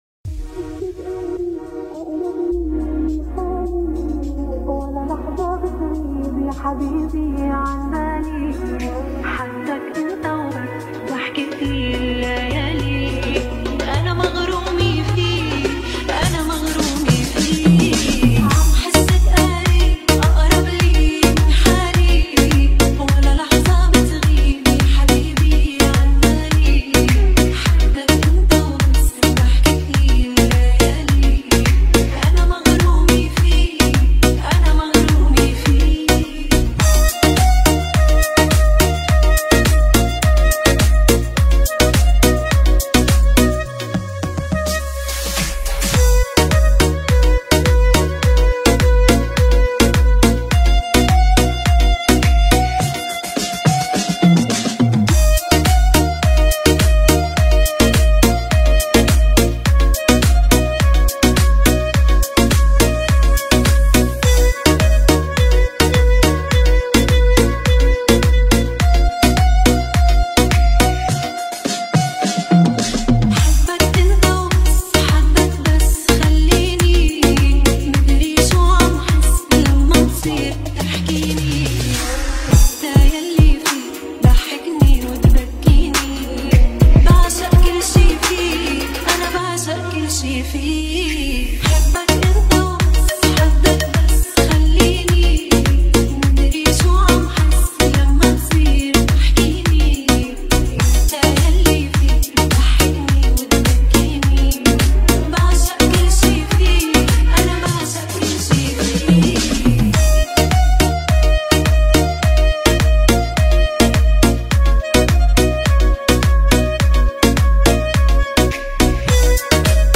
Arabic Remix